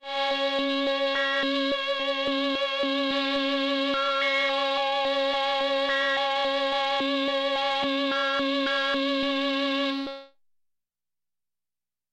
描述：通过Modular Sample从模拟合成器采样的单音。
Tag: CSharp5 MIDI音符-73 Korg的-Z1 合成器 单票据 多重采样